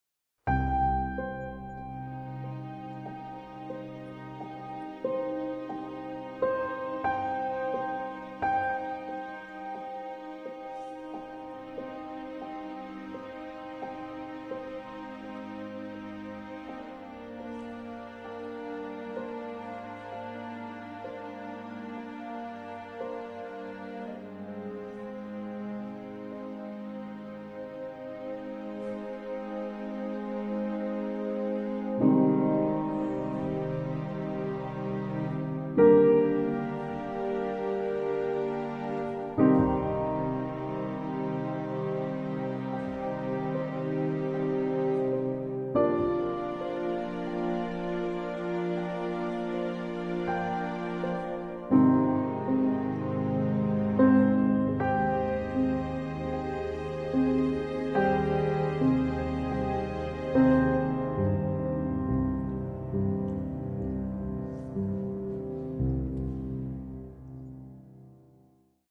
【１. 地圖背景音樂】